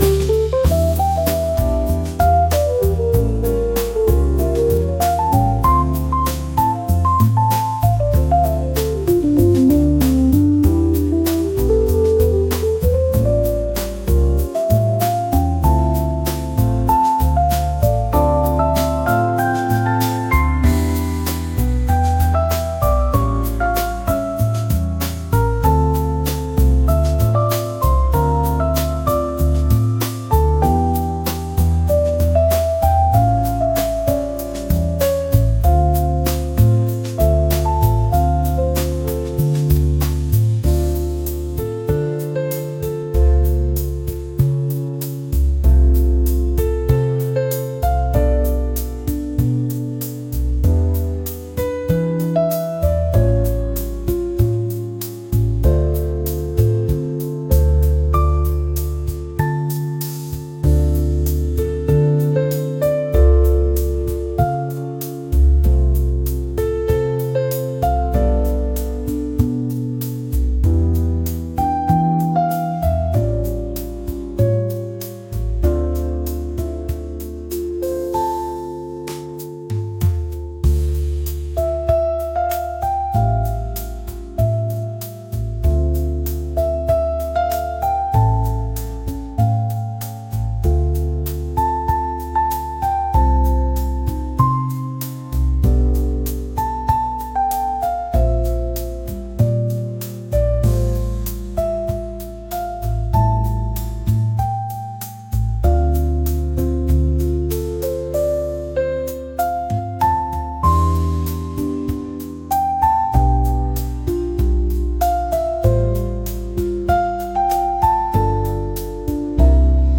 lounge | smooth